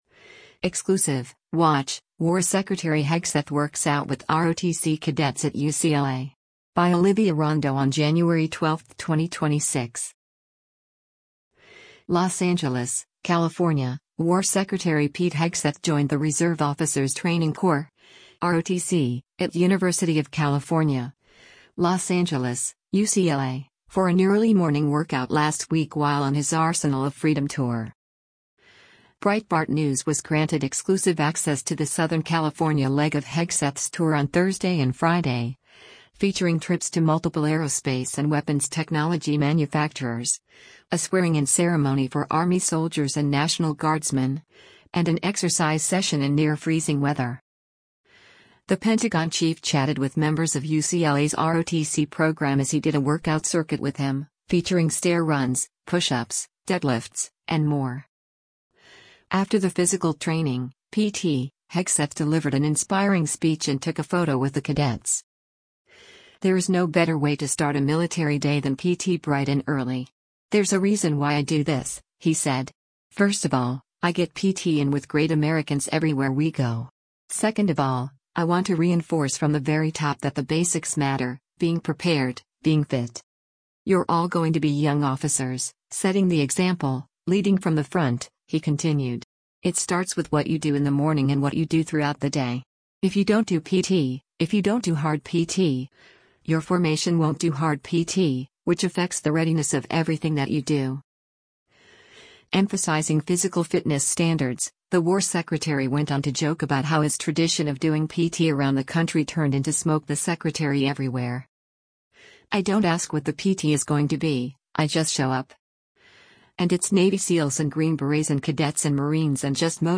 LOS ANGELES, California — War Secretary Pete Hegseth joined the Reserve Officers’ Training Corps (ROTC) at University of California, Los Angeles (UCLA) for an early-morning workout last week while on his “Arsenal of Freedom” tour.
The Pentagon chief chatted with members of UCLA’s ROTC program as he did a workout circuit with them, featuring stair runs, pushups, deadlifts, and more:
After the physical training (PT), Hegseth delivered an inspiring speech and took a photo with the cadets.